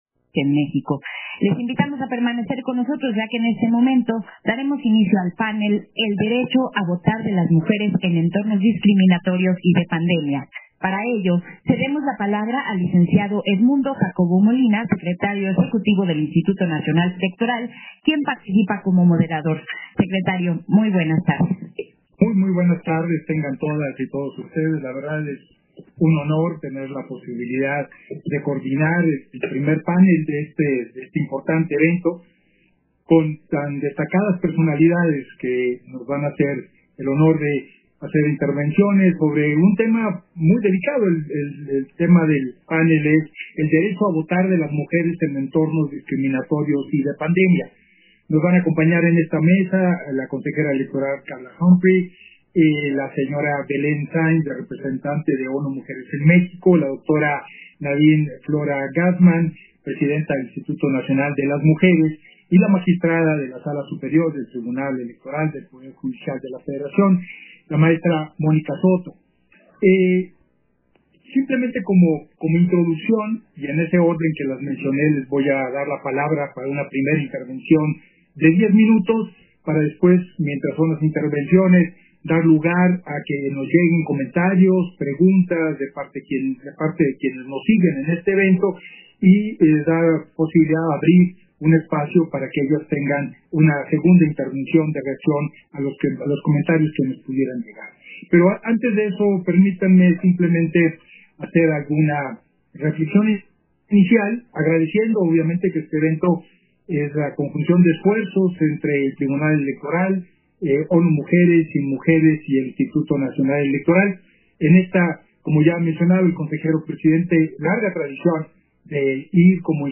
191020_AUDIO_PANEL_-EL-DERECHO-A-VOTAR-DE-LAS-MUJERES-EN-ENTORNOS-DISCRIMINATORIOS